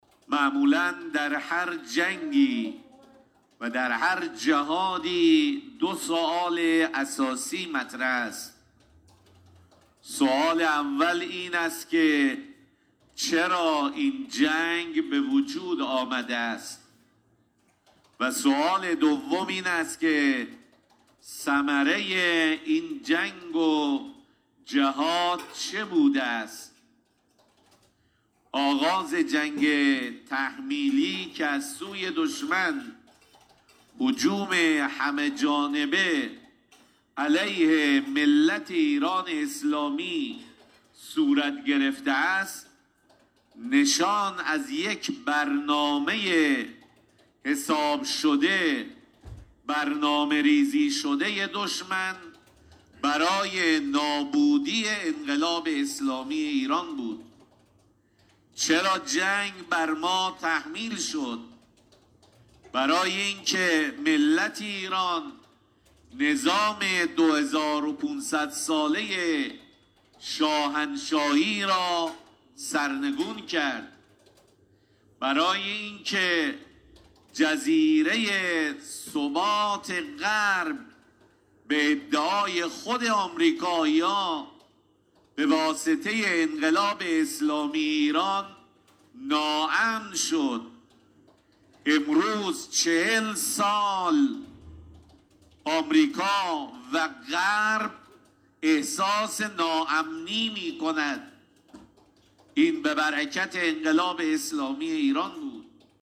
به گزارش خبرنگار خبرگزاری رسا در خرم آباد، حجت الاسلام والمسلمین سیداحمد میرعمادی، نماینده ولی فقیه در لرستان و امام جمعه خرم آباد، صبح امروز در مراسم رژه نیروهای مسلح لرستان با گرامیداشت یاد و خاطره شهدای دفاع مقدس گفت: دفاع مقدس یادآور شجاعت، استقامت و رشادت رادمردانی است که عزت، عظمت، استقلال، آزادی و معنویت را به ارمغان آوردند.